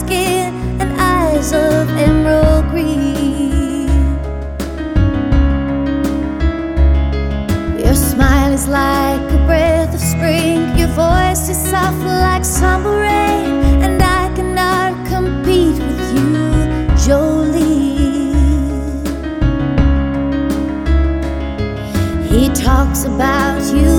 With Lead Vocals